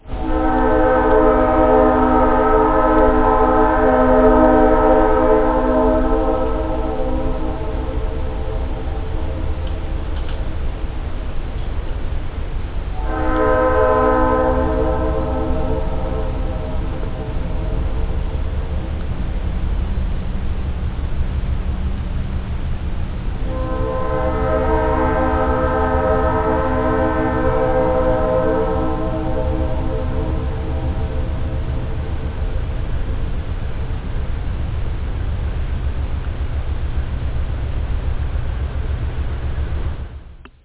دانلود صدای بوق قطار از دور از ساعد نیوز با لینک مستقیم و کیفیت بالا
جلوه های صوتی
برچسب: دانلود آهنگ های افکت صوتی حمل و نقل